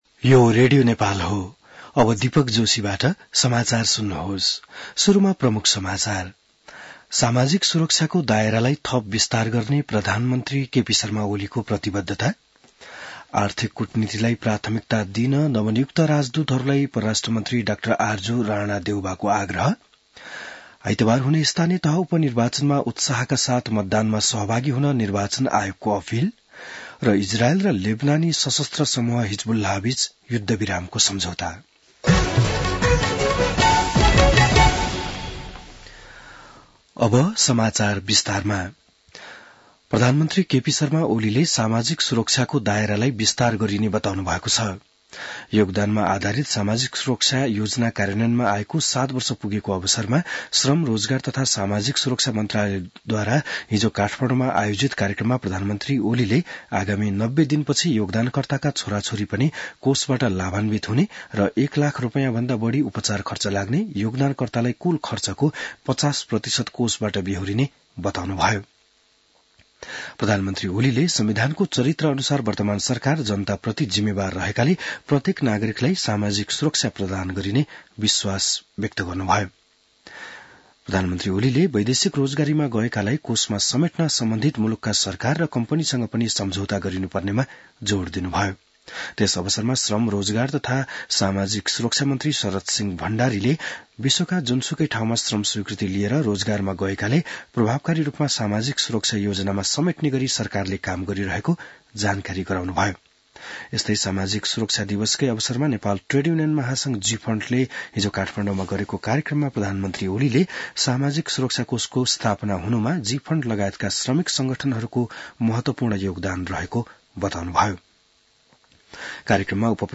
An online outlet of Nepal's national radio broadcaster
बिहान ९ बजेको नेपाली समाचार : १३ मंसिर , २०८१